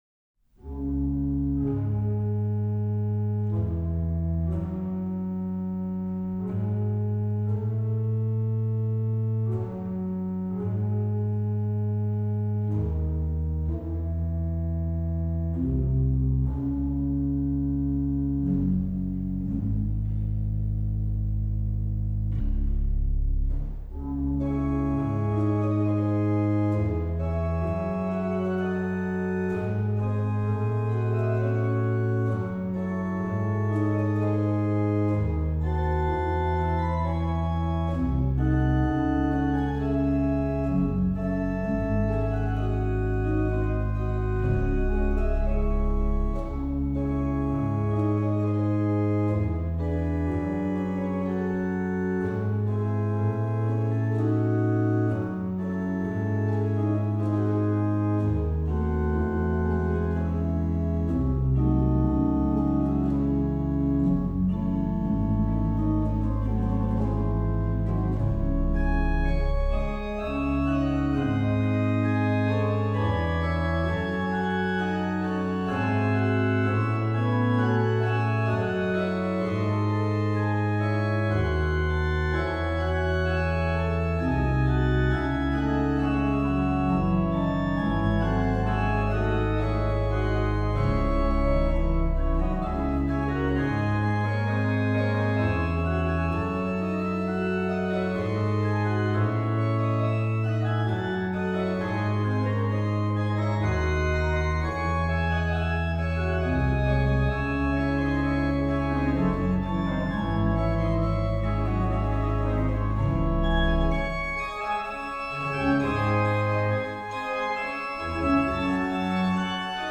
Bach Organ Works
Passacaglia and Fugue in C MInor Venue 1724-30 Trost organ, Stadtkirche, Waltershausen, Germany